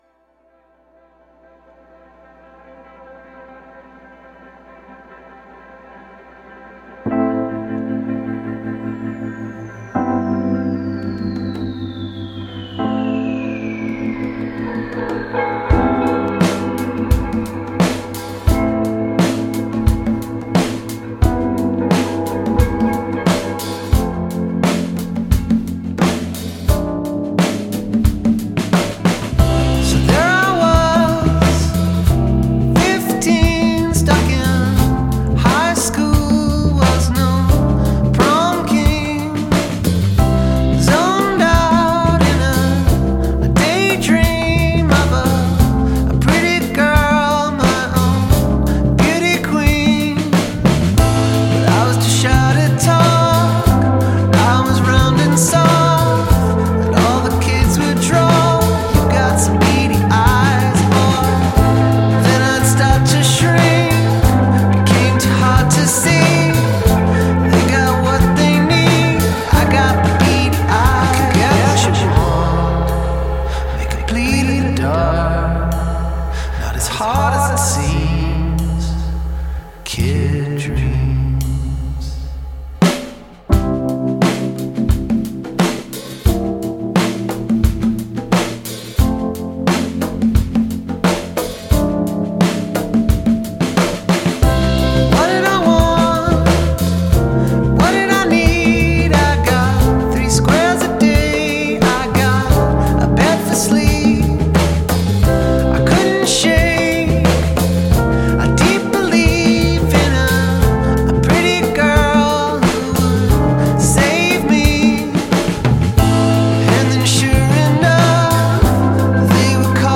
overflows with more feel-good tunefulness.
poignant